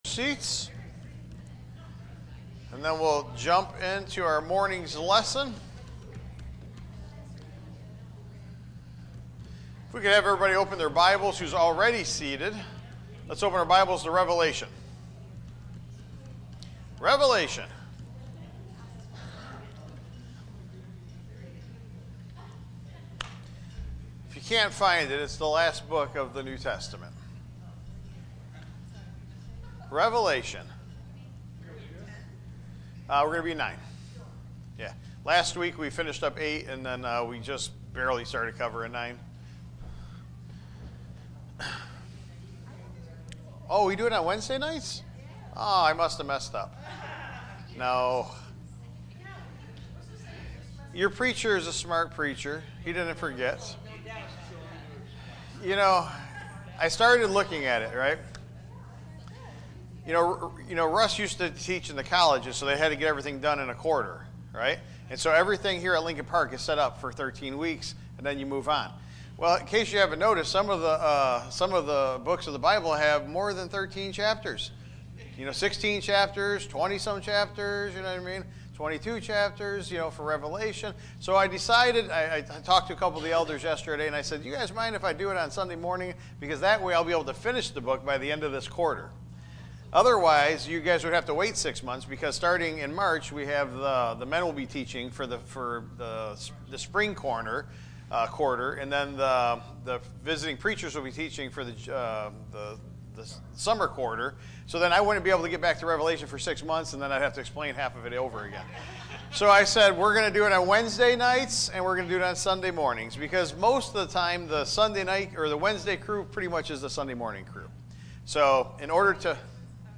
Taught live